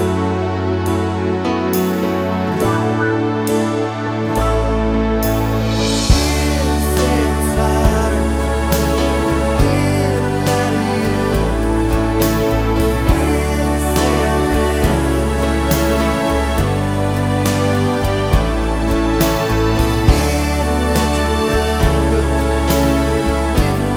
Two Semitones Down Pop (2000s) 3:39 Buy £1.50